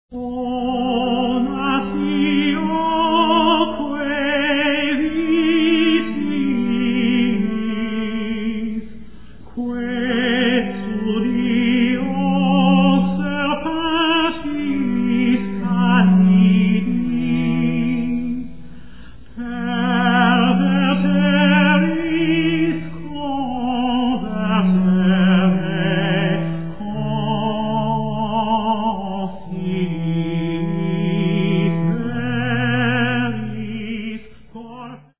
This rare performance features an exceptional vocal ensemble
countertenor
viol